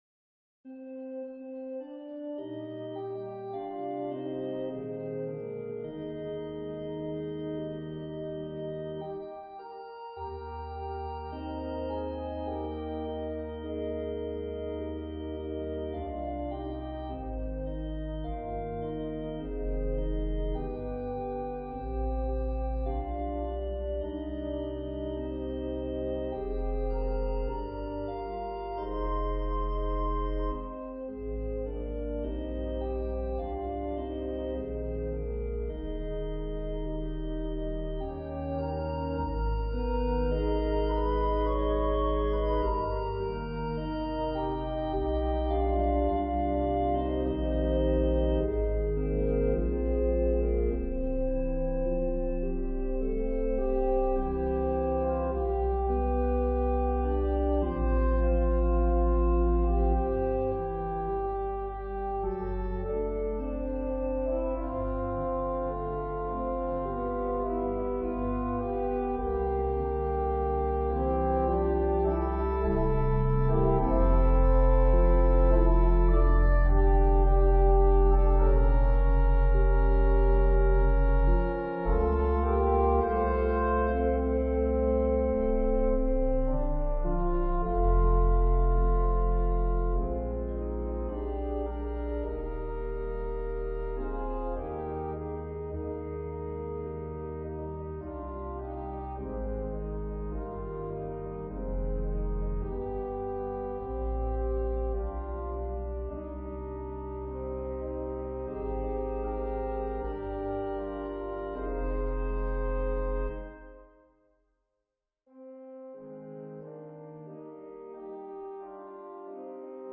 Voicing/Instrumentation: Organ/Organ Accompaniment
An organist who likes to arrange music for organ.